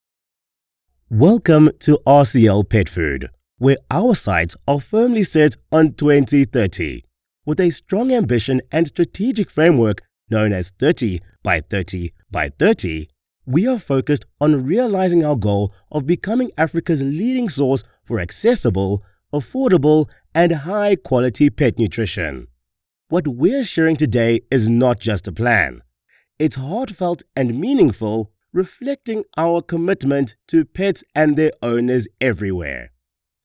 Englisch (südafrikanisch)
Unternehmensvideos
- Warme, dynamische und professionelle Stimme
- Hochwertige Aufnahmen aus meinem modernen Homestudio
Audio Technica AT2020 Nieren-Kondensatormikrofon